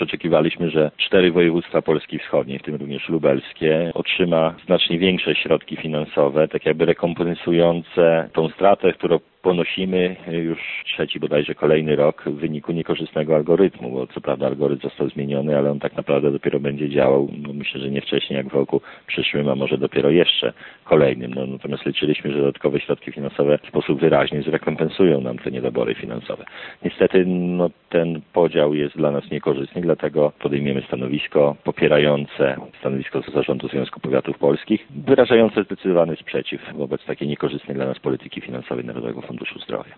Innym tematem obrad będzie podział dodatkowych pieniędzy, którymi dysponuje Narodowy Fundusz Zdrowia. Według starosty lubelskiego propozycje NFZ są dla szpitali na Lubelszczyźnie niekorzystne: